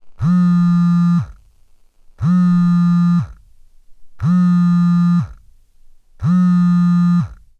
Звук вибрации телефона:
zvuk-vibracii-telefona.mp3